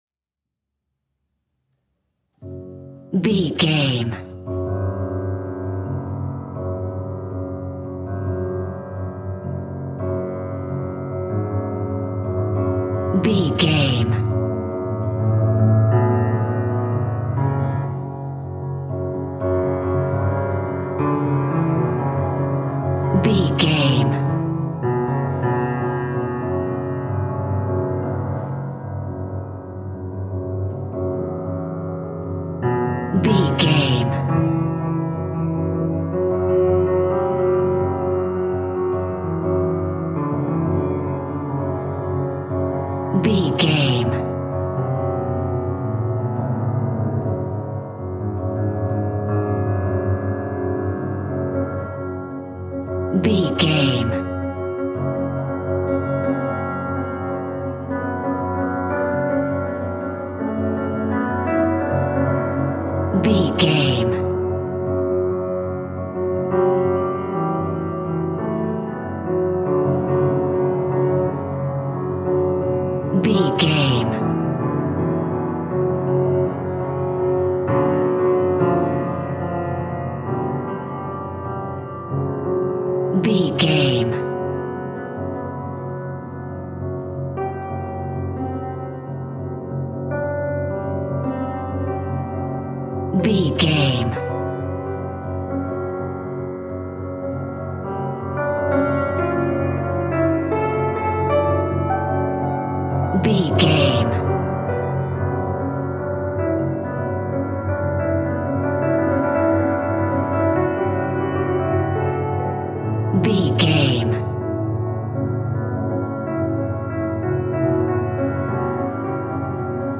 Scary Haunting Music Cue.
Aeolian/Minor
ominous
eerie
horror music
horror piano